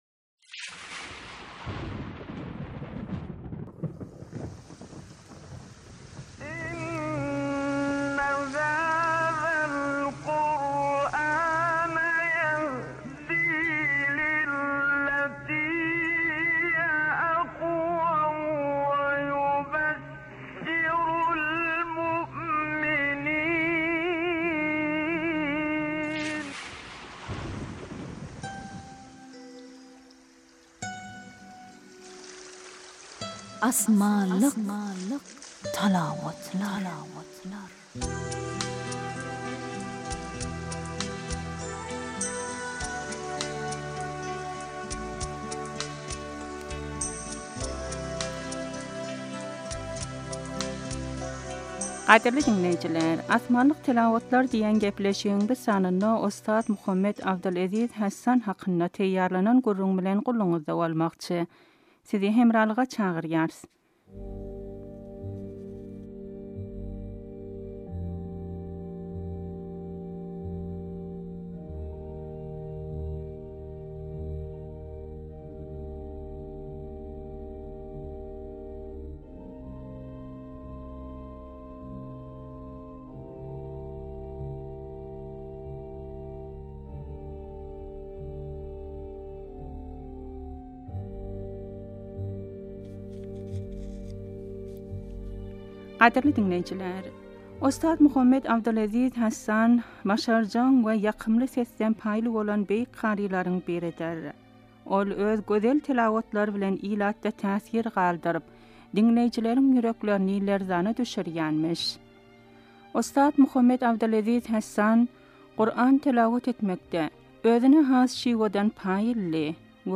Asmanlik talawatlar